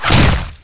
staffhit_2.wav